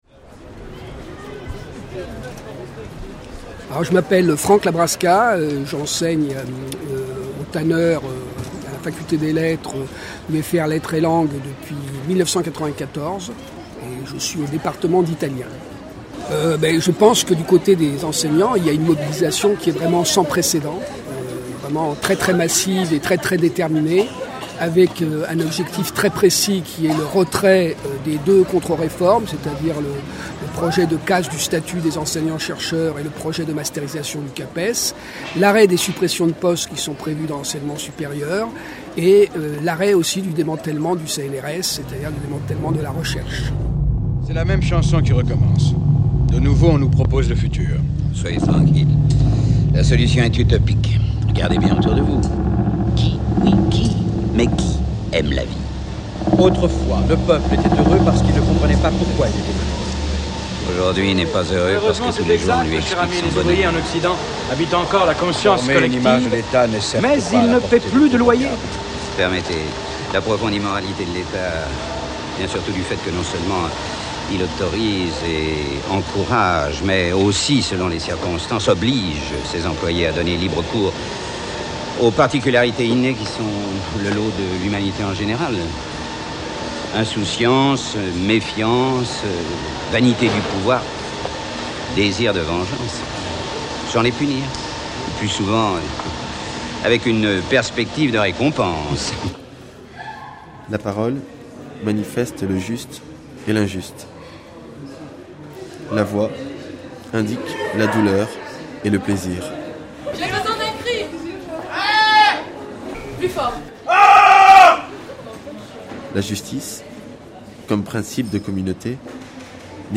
Reportage sur la gr�ve illimit�e ouverte par les enseignants-chercheurs en f�vrier 2009.